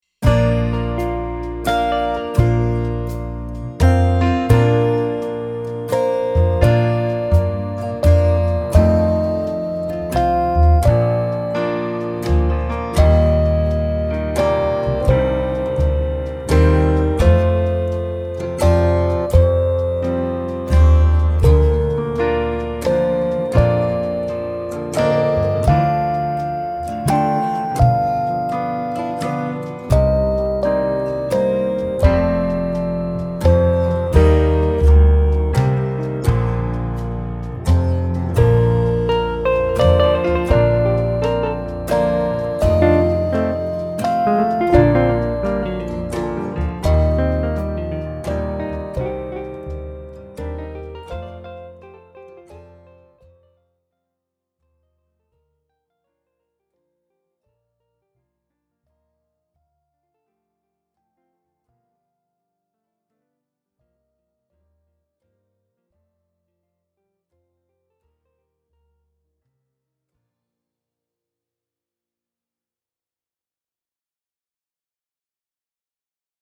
Maza dziesmiņa Play-along.